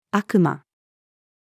悪魔-female.mp3